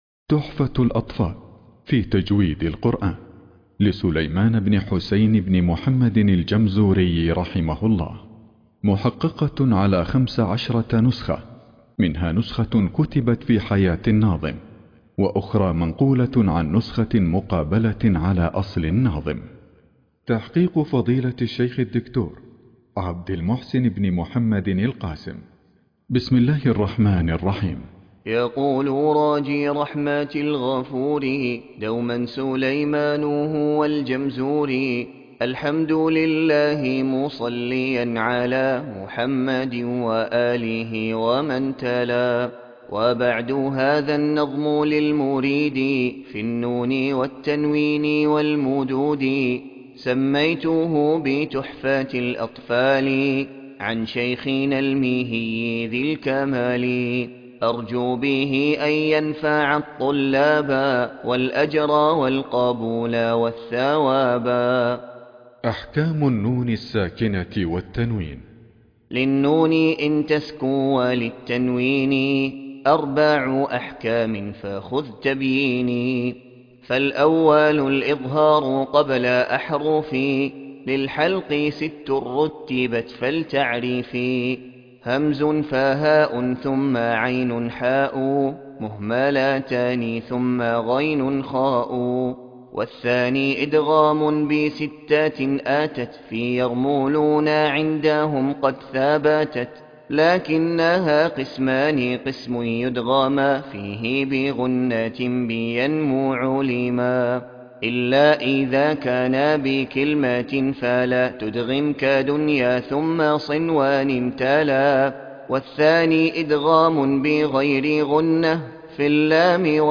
تحفة الأطفال في تجويد القرآن _ قراءة